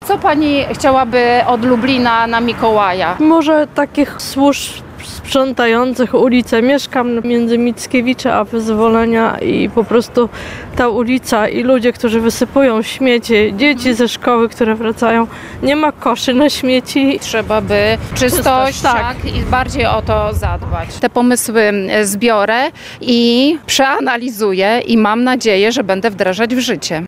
Wiceprzewodnicząca rady miasta Lublin Monika Kwiatkowska – z okazji mikołajek – zbierała życzenia mieszkańców Lublina.